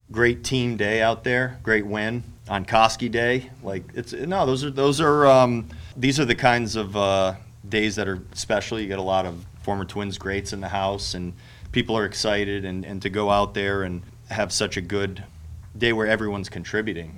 Twins manager Rocco Baldelli says this was a special team win.